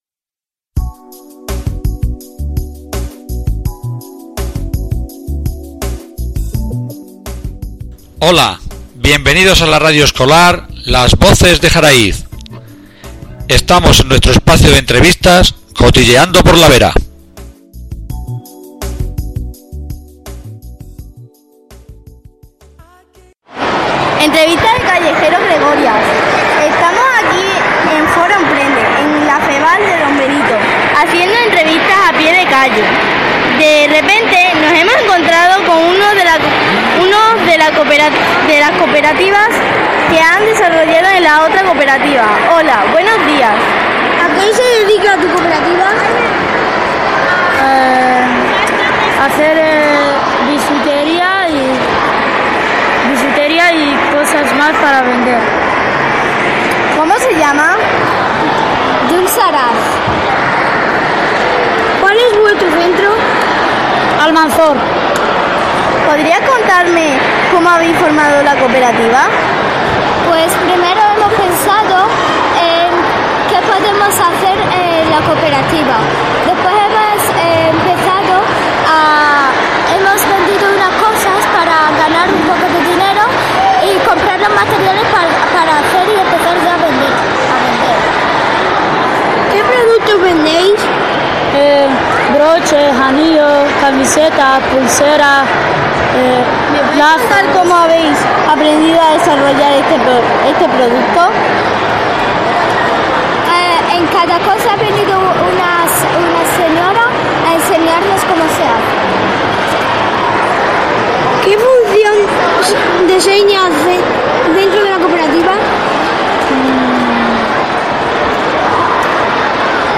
ENTREVISTAS COOPERATIVAS FORO EMPRENDE